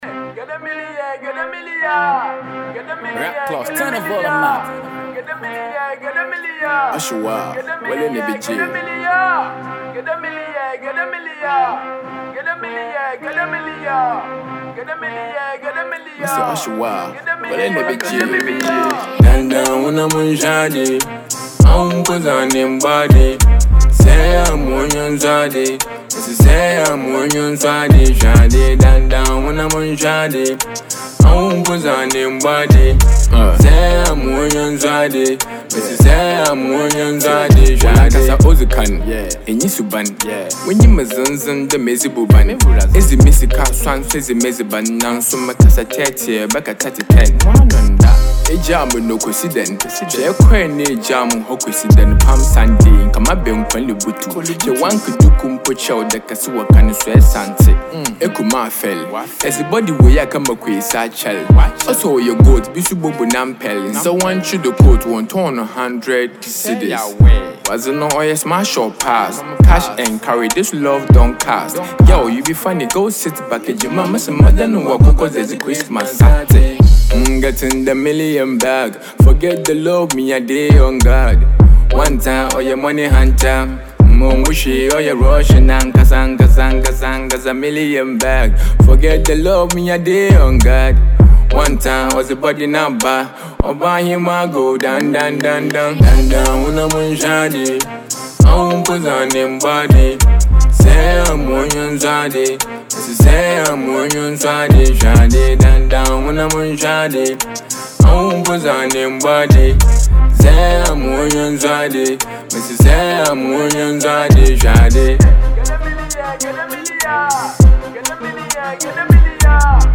a Fantse dope rapper
infectious tune
This is a banger all day.